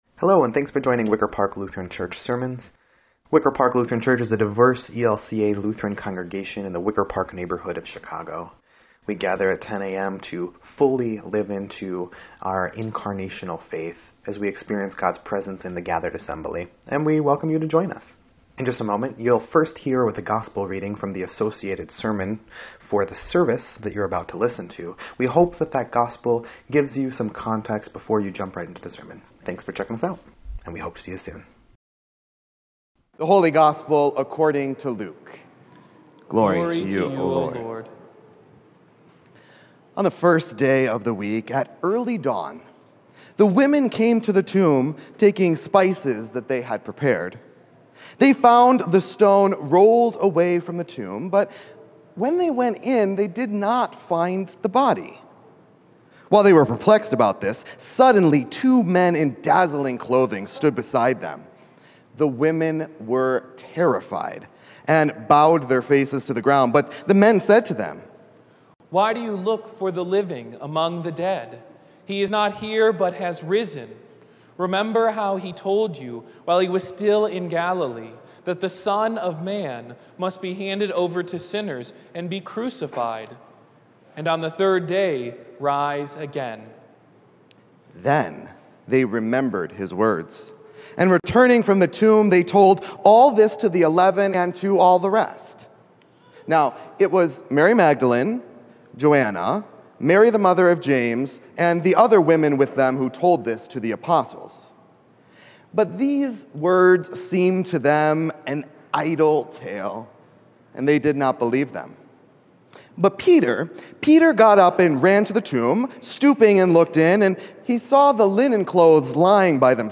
4.17.22-Sermon_EDIT.mp3